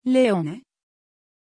Pronuncia di Leone
pronunciation-leone-tr.mp3